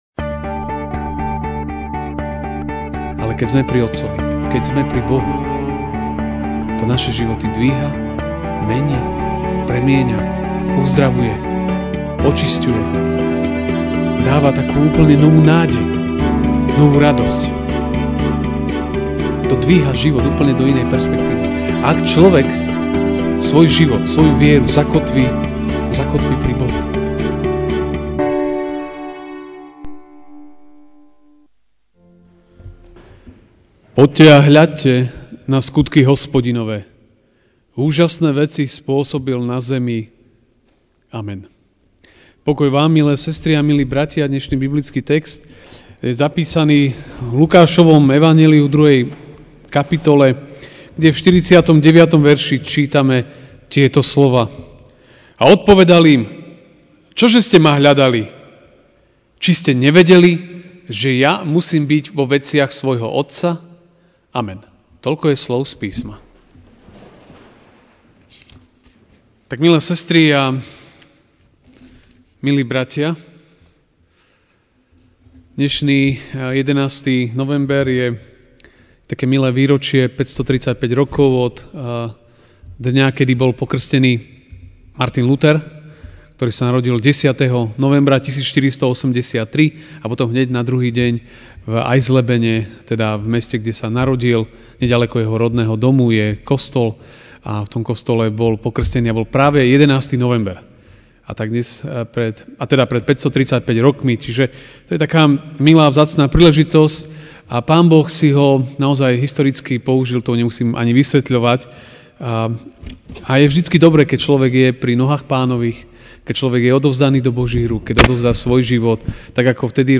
Večerná kázeň: Byť v prítomnosti Boha (L 2, 49)Odpovedal im: Čo, že ste ma hľadali?